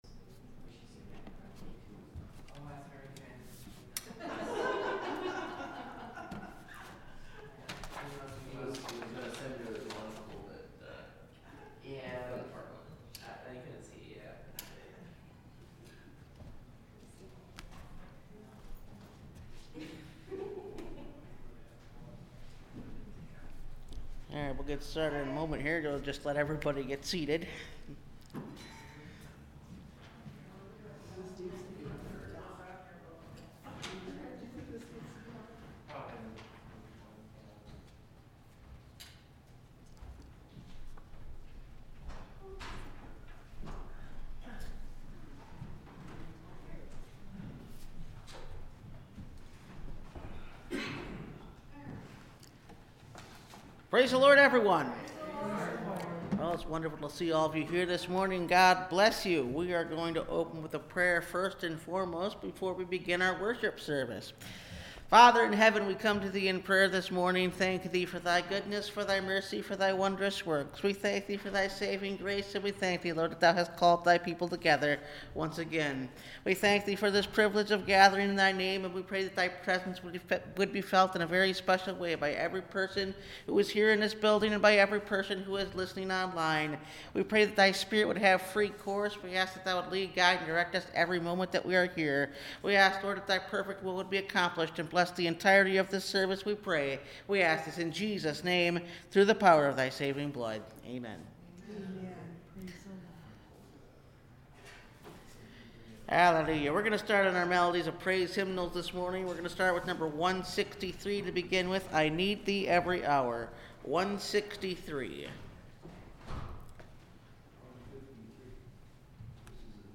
By Grace Are Ye Saved – Last Trumpet Ministries – Truth Tabernacle – Sermon Library